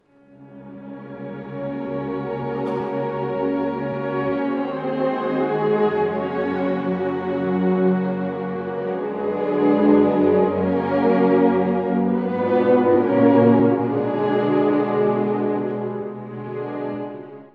さらに印象的なのが第二主題です。
Fis-dur…とても深く、神々しい調性。フィレンツェで得たキリストの救済のイメージかもしれません。